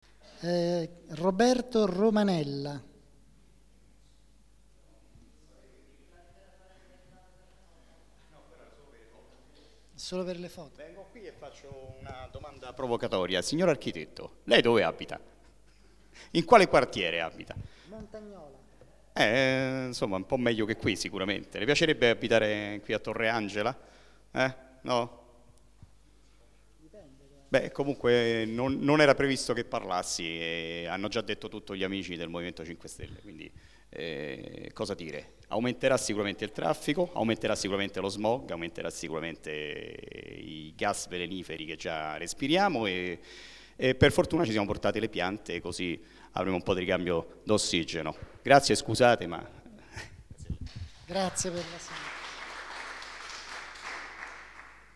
Assemblea Partecipativa TBMRegistrazione integrale dell'incontro svoltosi il 13 settembre 2012 presso la sala consiliare del Municipio VIII in Via D. Cambellotti, 11.